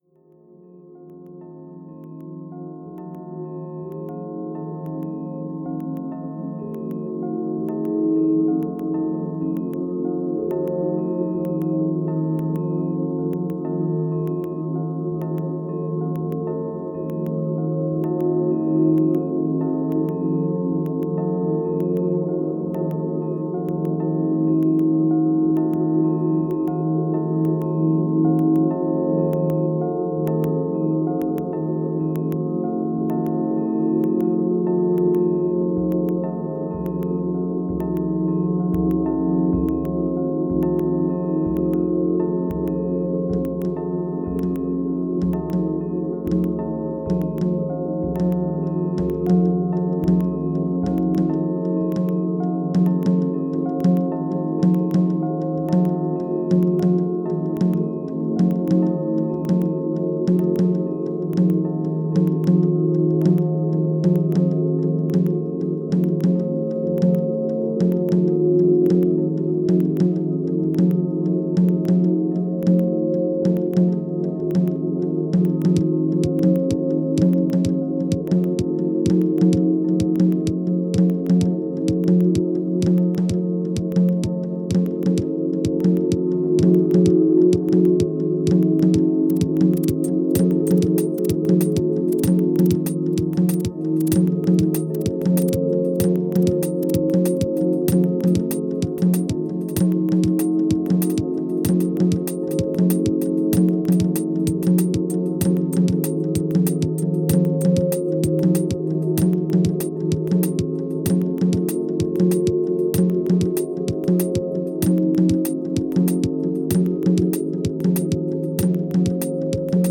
Kleines_Polyrhythmus_Wendy_Carlos_Scale_Experiment_zum_einschlummern.mp3